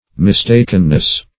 Mistakenness \Mis*tak"en*ness\, n.
mistakenness.mp3